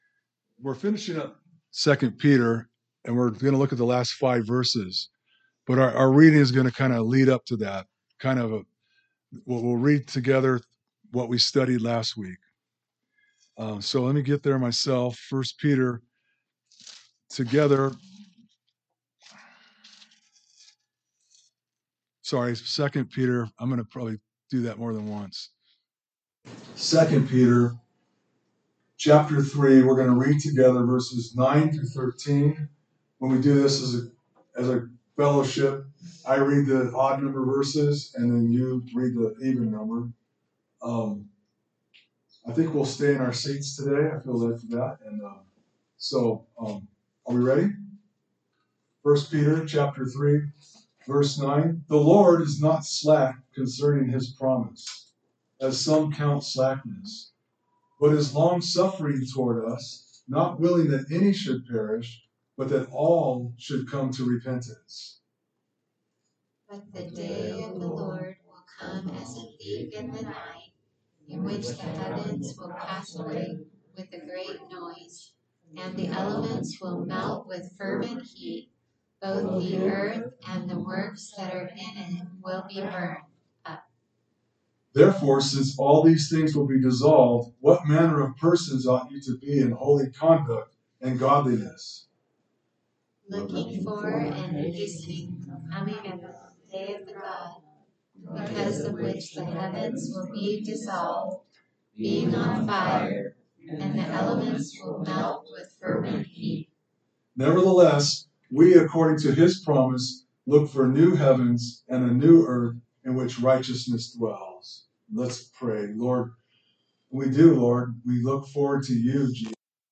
A message from the series "2 Peter."